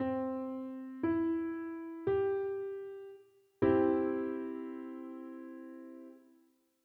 The audio examples in this course will always play triads both melodically and harmonically.
C-Major-Triad-S1.wav